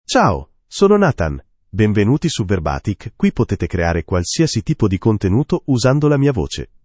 Nathan — Male Italian (Italy) AI Voice | TTS, Voice Cloning & Video | Verbatik AI
Nathan is a male AI voice for Italian (Italy).
Voice sample
Listen to Nathan's male Italian voice.
Nathan delivers clear pronunciation with authentic Italy Italian intonation, making your content sound professionally produced.